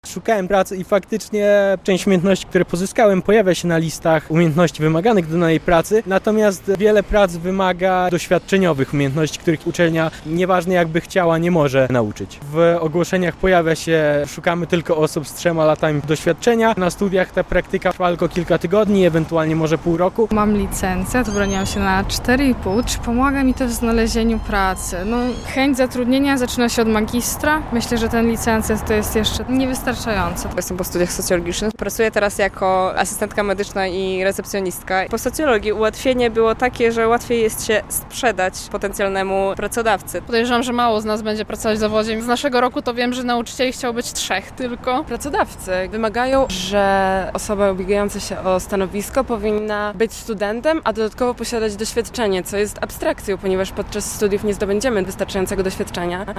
Co dalej z absolwentami białostockich uczelni - relacja